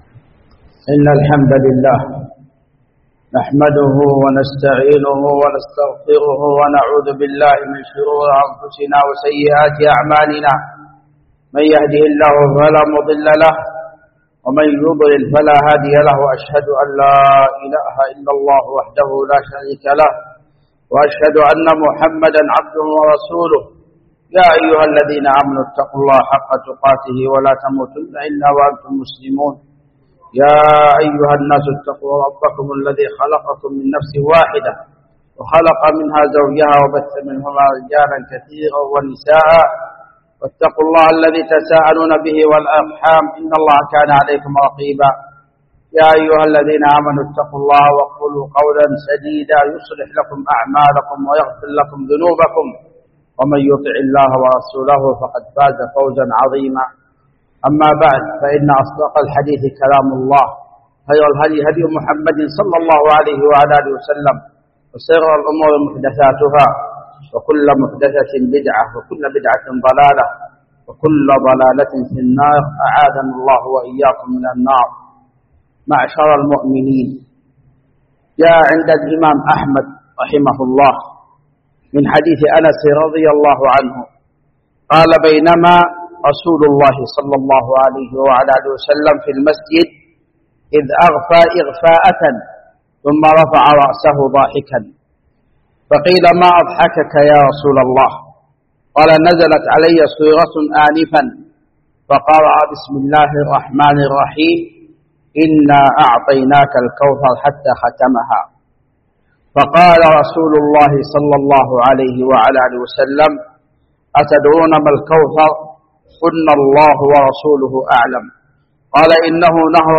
خطبة
جامع الملك عبدالعزيز باسكان الخارش بصامطة